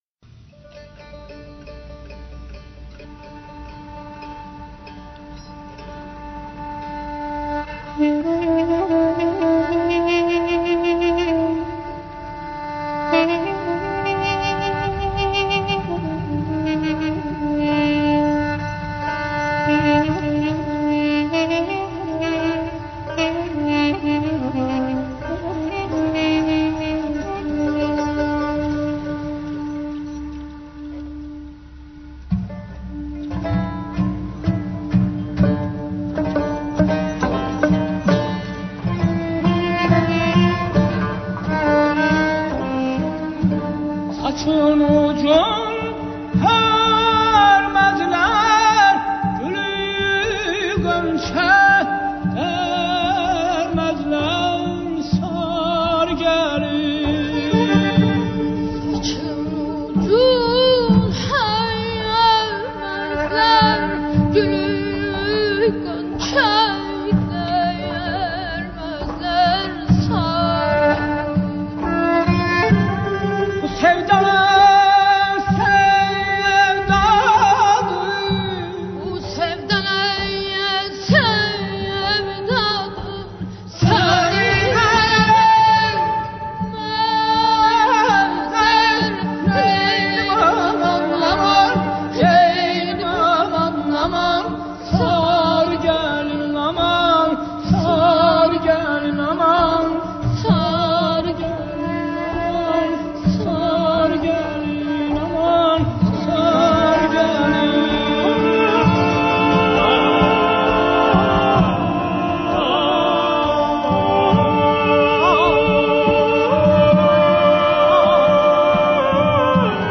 موسیقی فولکلور ایرانی